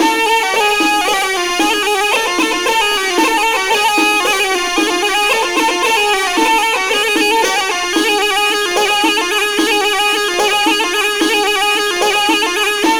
DRUM+PIPE1-R.wav